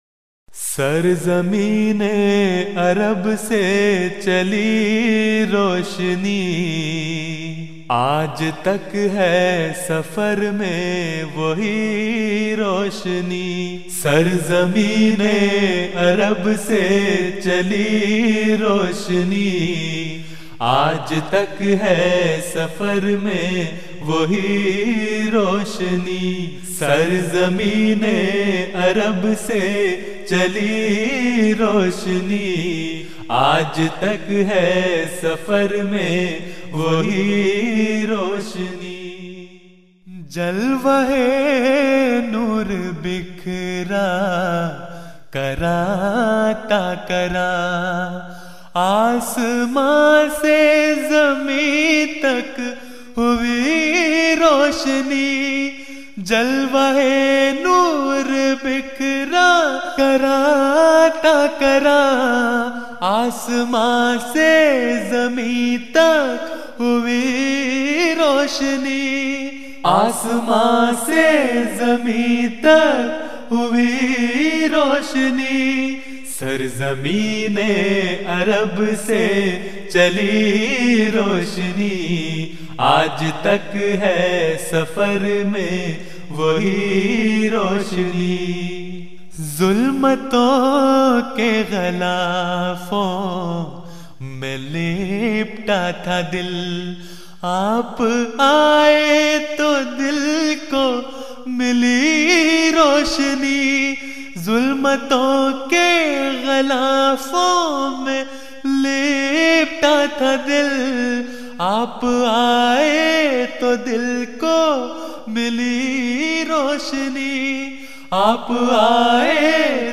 Urdu Poems
Voice: Member Lajna Ima`illah
Jalsa Salana Germany 2011